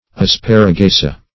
Asparagaceae - definition of Asparagaceae - synonyms, pronunciation, spelling from Free Dictionary